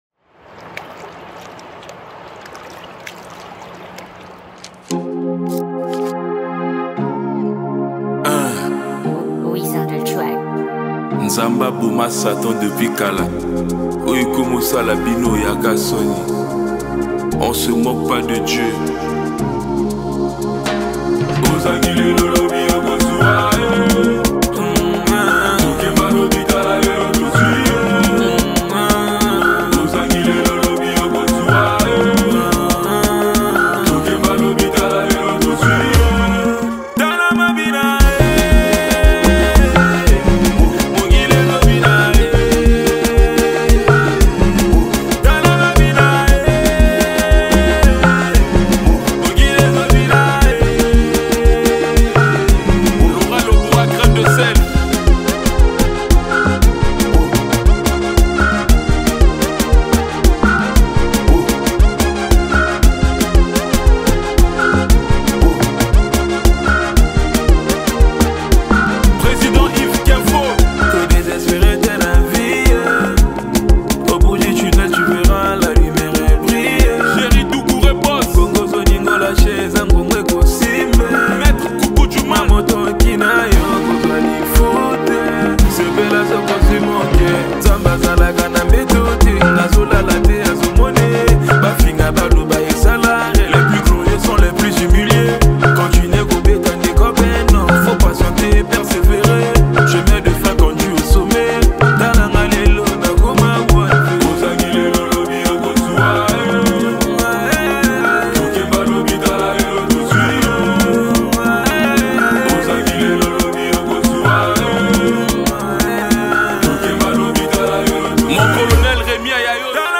| Afrobeat